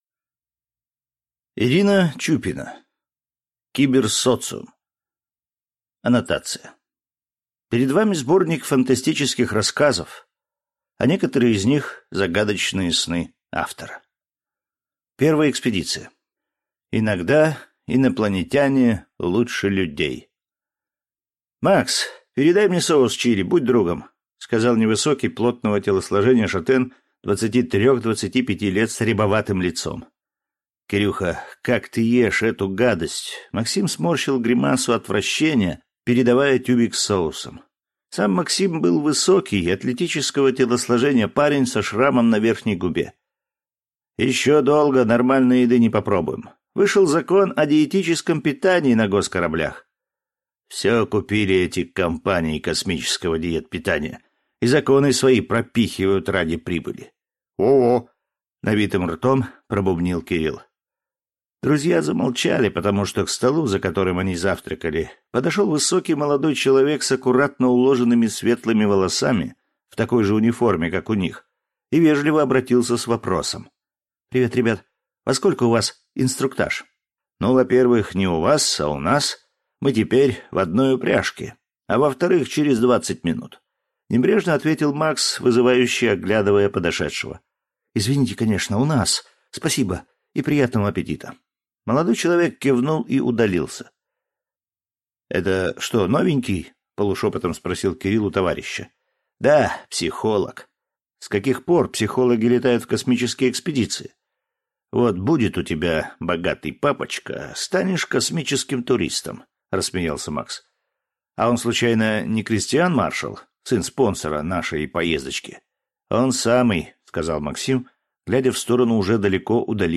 Аудиокнига Киберсоциум | Библиотека аудиокниг
Прослушать и бесплатно скачать фрагмент аудиокниги